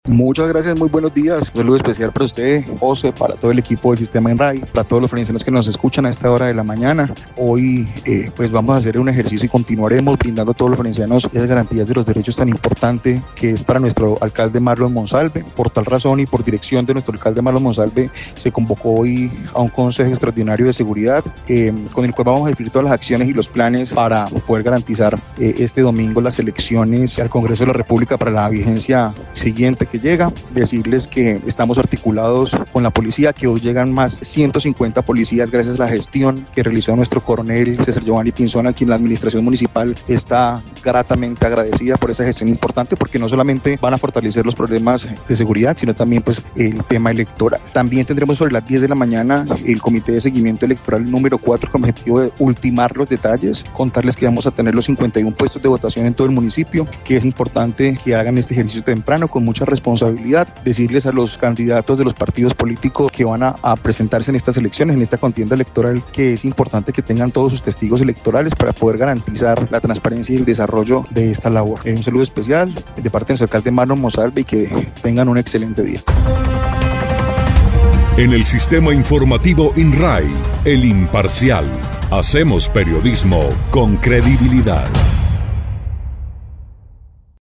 Así lo dio a conocer el secretario de gobierno del municipio, Neomeyer Cuéllar Castro, quien dijo que, la mañana de este miércoles se definen los horarios de aplicabilidad de la Ley seca junto a otras restricciones pensadas para preservar el orden público antes, durante y después de elecciones.